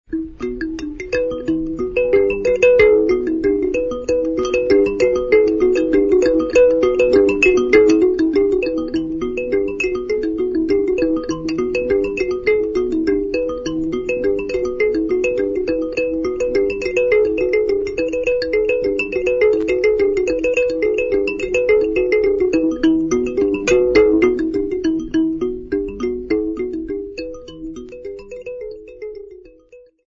Self delectative instrumental
Indigenous folk music
Kankowele mbira
Broken Hill mine
Northern Rhodesia (Zambia)
kankowele mbira (11 reeds, 2 manuals, fan-shaped with mirliton and held over a small resonator gourd)
Original format: 15ips reel
Hugh Tracey (Recorded by)